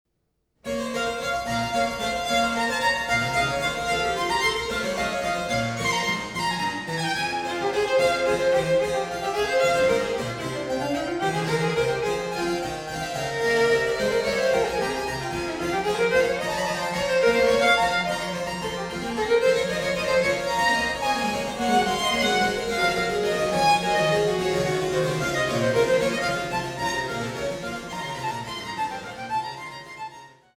Tasteninstrumente
Barockvioline
Cello